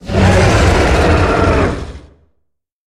Sfx_creature_squidshark_idle_01.ogg